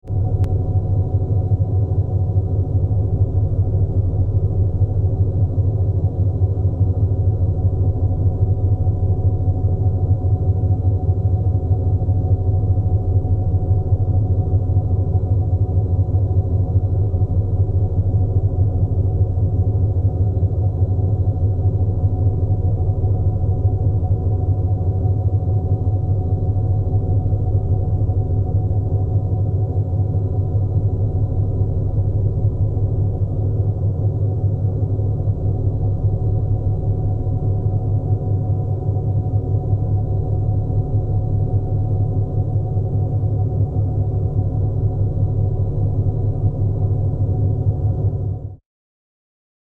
دانلود آهنگ باد 56 از افکت صوتی طبیعت و محیط
جلوه های صوتی
دانلود صدای باد 56 از ساعد نیوز با لینک مستقیم و کیفیت بالا